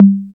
TR808TOM4.wav